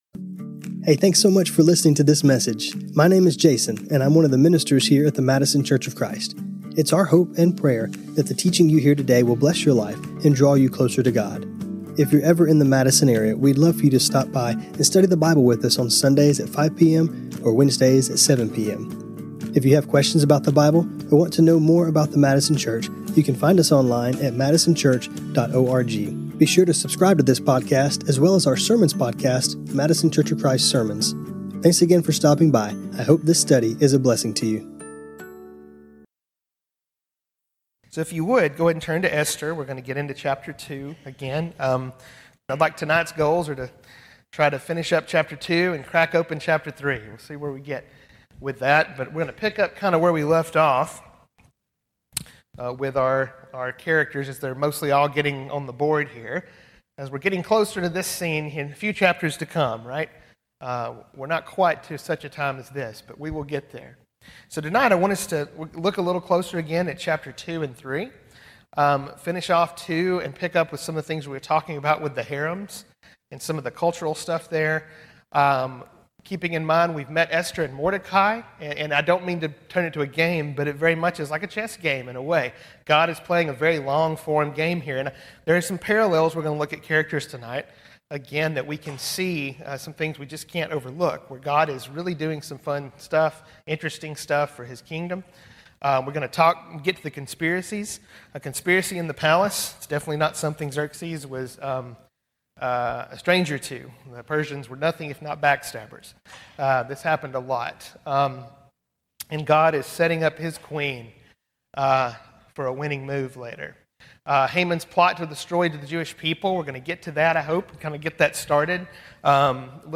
This class was recorded on Mar 4, 2026.